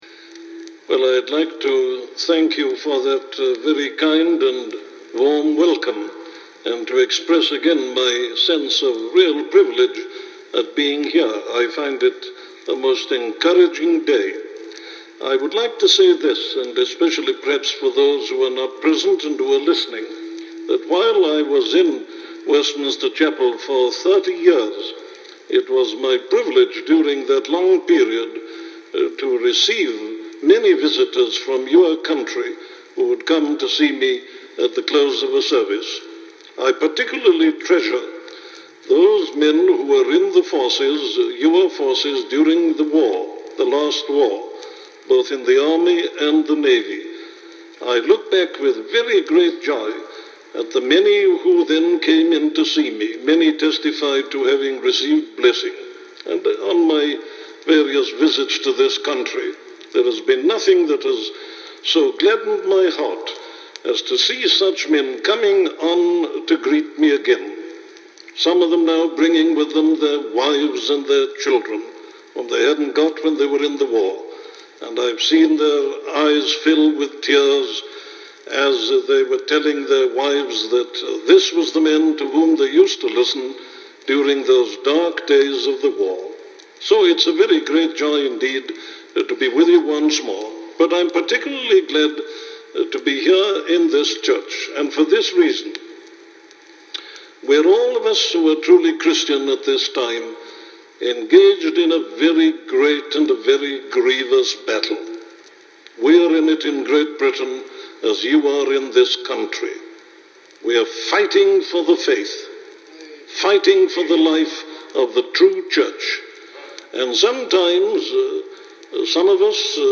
What is a Christian? - a sermon from Dr. Martyn Lloyd Jones